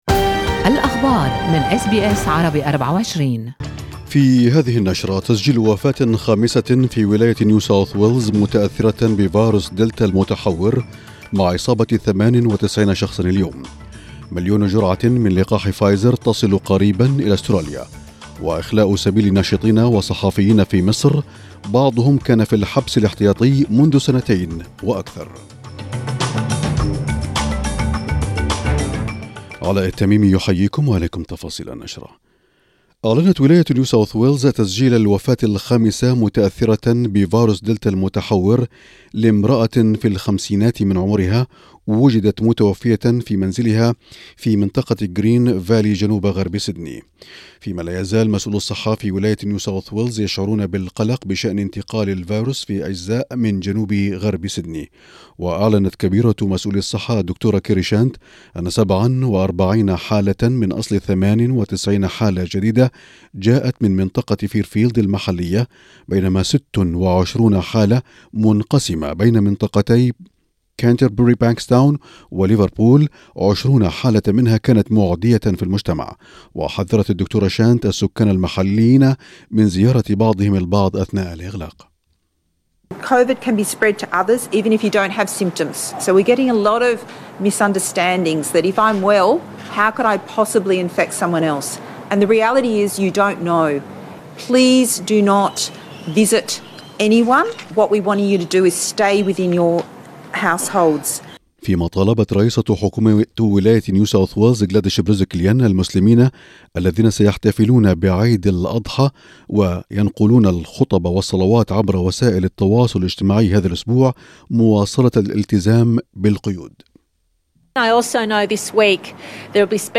نشرة اخبار المساء 19/7/2021